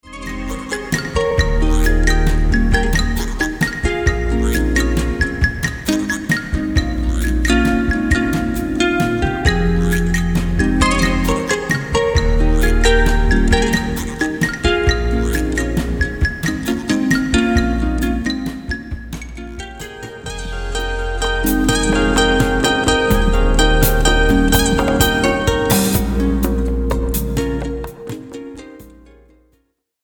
at the SINUS-Studio Bern (Switzerland)